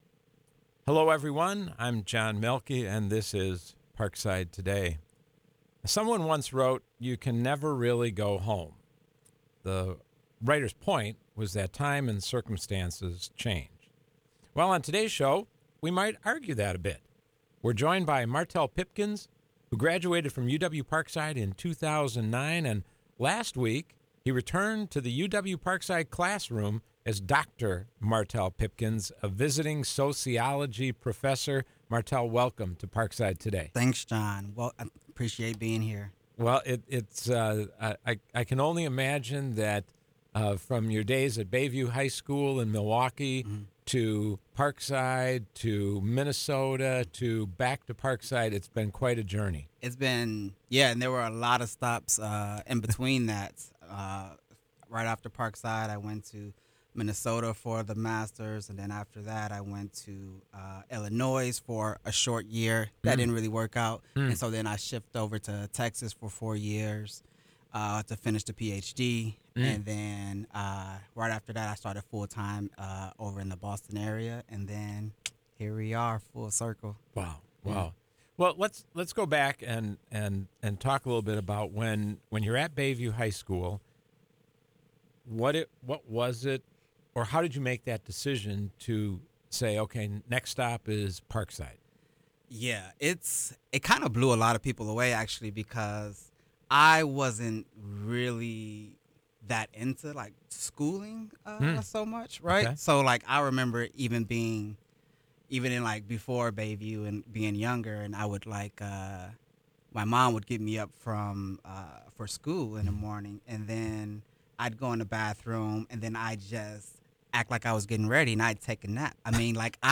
This show originally aired on Tuesday, September 10, at 4 p.m. on WIPZ 101.5 FM.